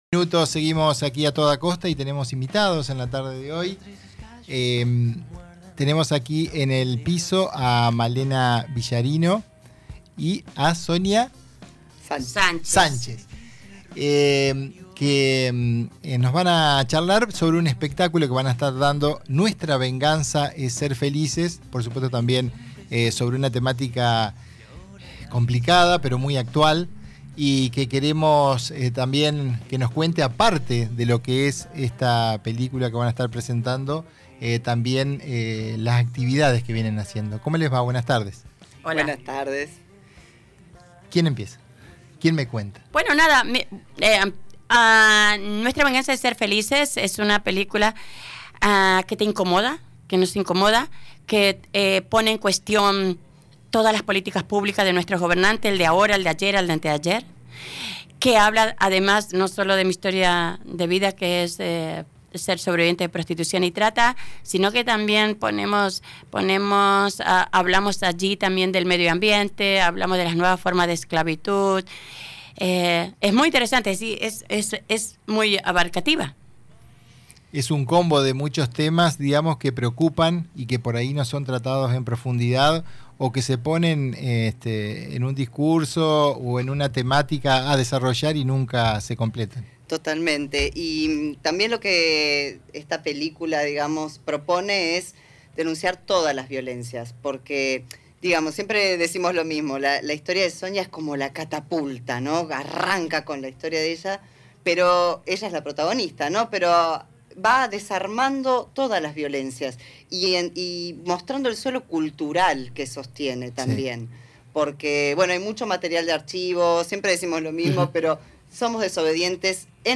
Ambas estuvieron en los estudios de radio “Costa Paraná”.